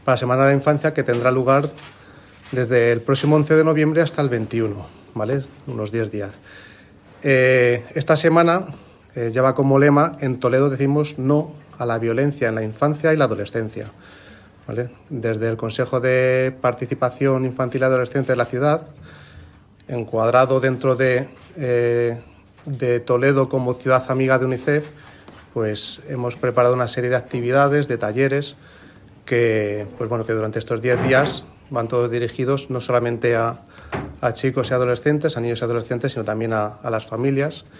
Morcillo, quien ha comparecido en rueda de prensa